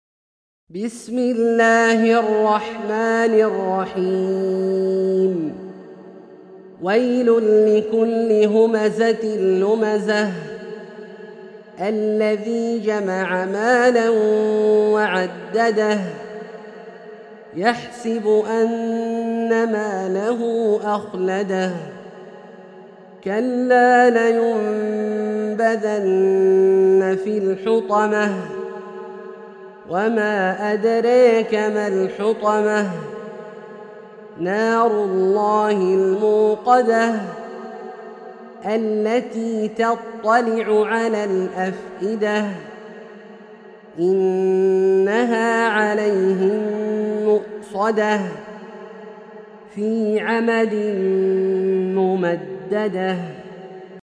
سورة الهمزة - برواية الدوري عن أبي عمرو البصري > مصحف برواية الدوري عن أبي عمرو البصري > المصحف - تلاوات عبدالله الجهني